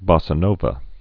(bŏsə nōvə, bôsə)